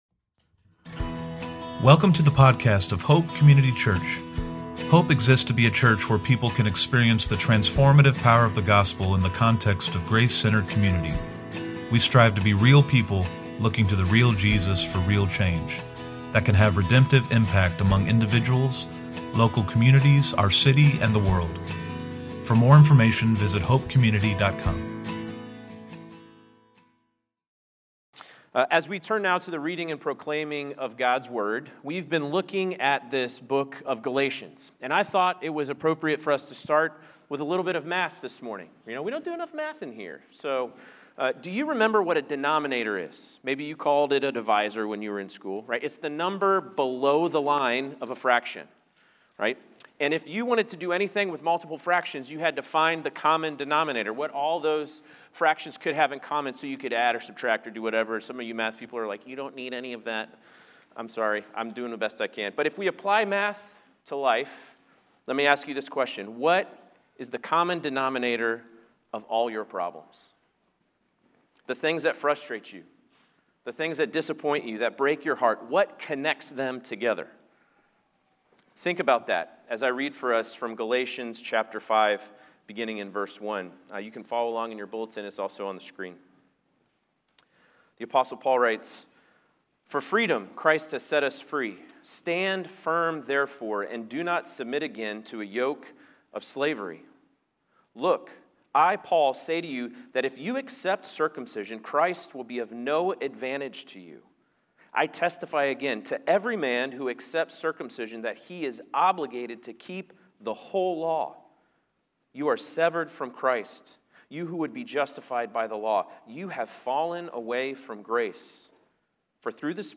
CW-Sermon-8.10.25.mp3